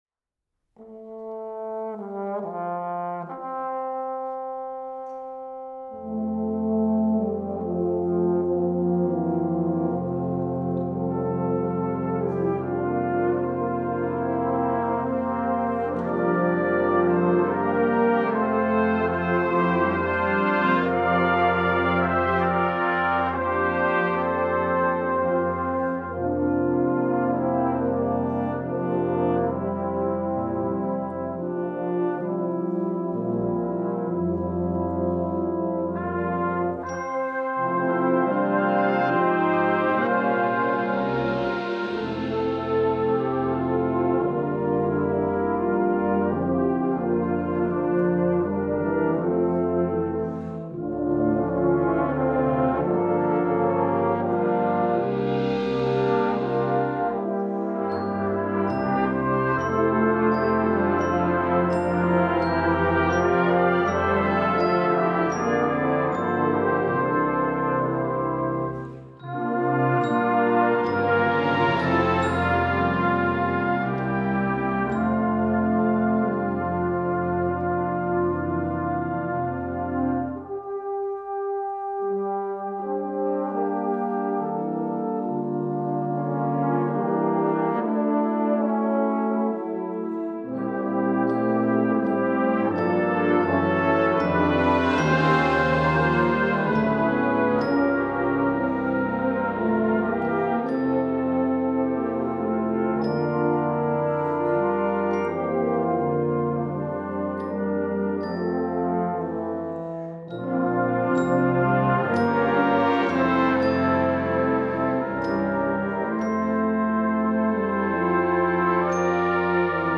(Meditation)